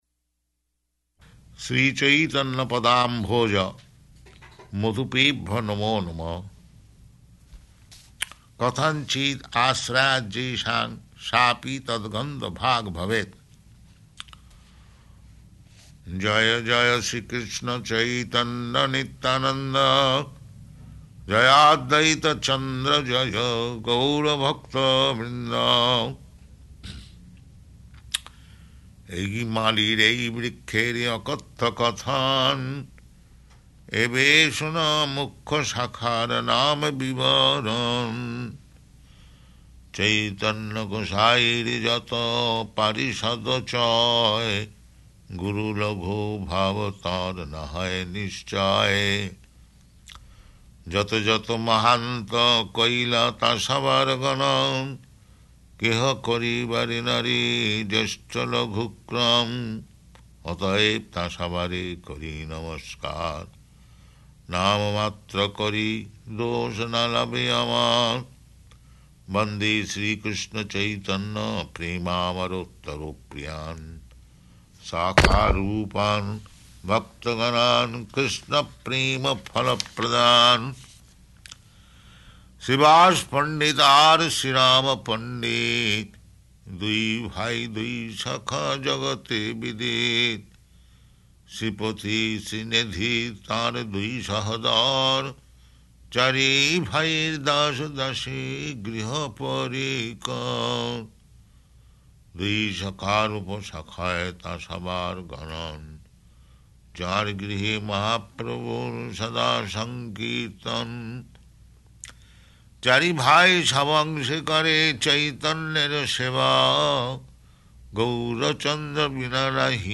Chanting Cc Ādi-līlā 10th and 11th Chapter